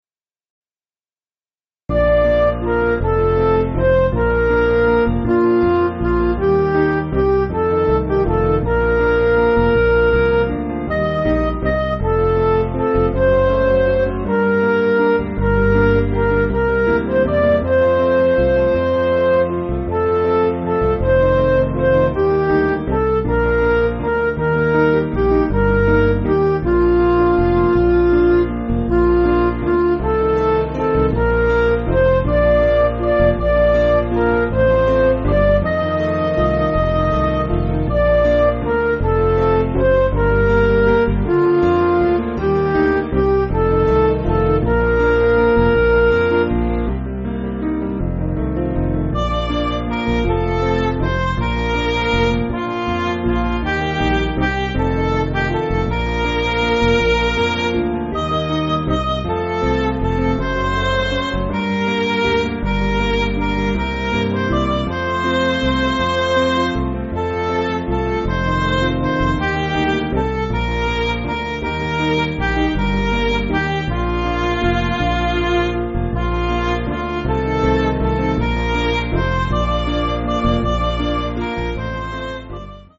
Piano & Instrumental
(CM)   3/Bb